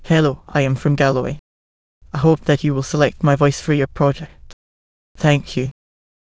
voices/VCTK_European_English_Males at main